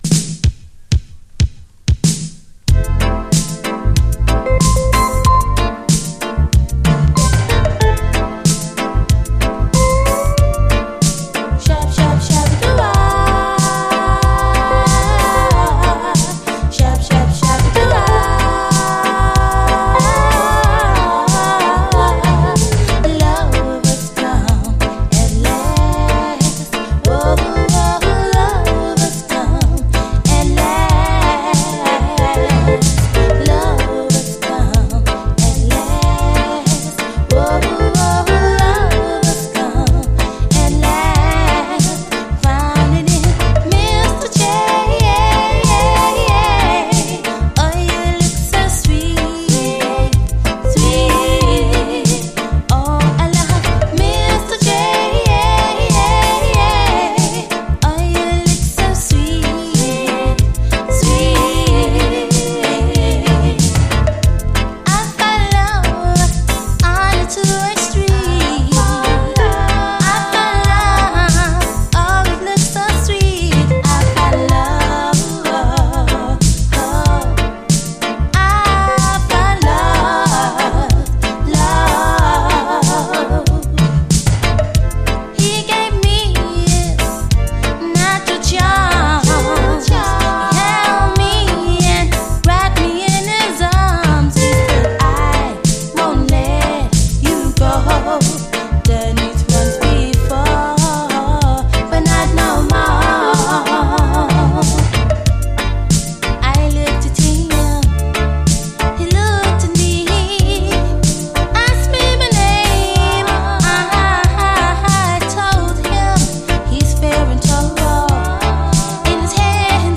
REGGAE
トロけるサウンドの最高80’S UKラヴァーズ！